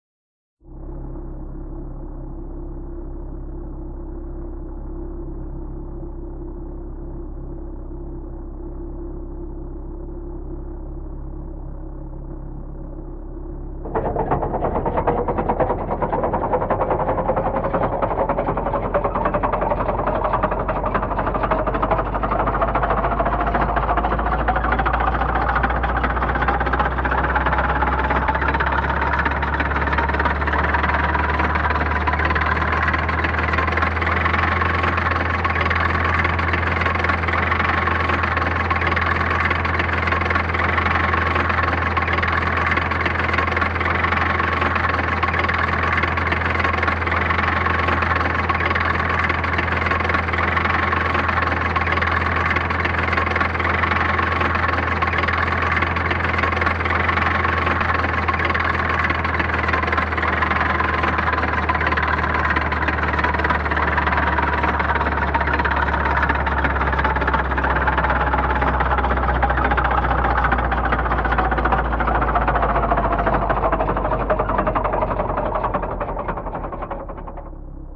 BSG FX - Landram - Idle, drive slow, stop
BSG_FX_-_Landram_-_Idle2C_drive_slow2C_stop.wav